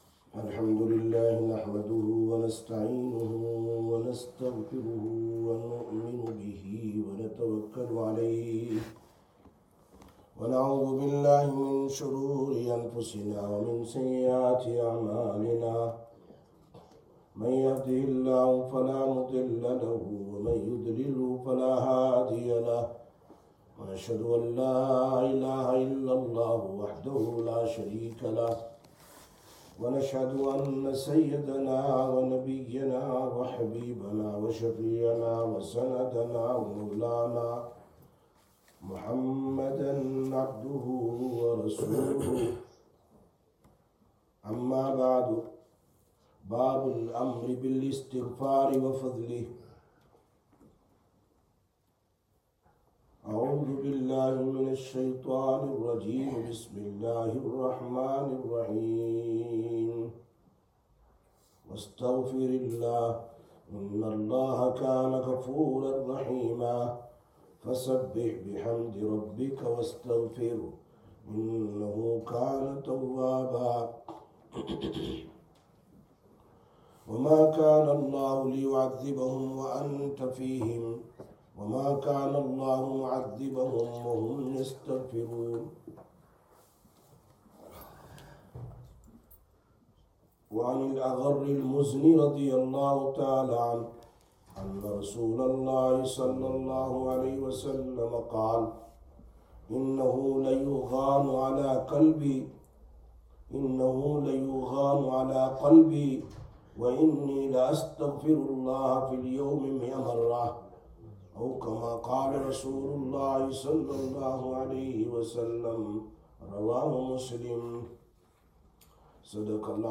11/03/2026 Sisters Bayan, Masjid Quba